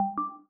Simple Cute Alert 27.wav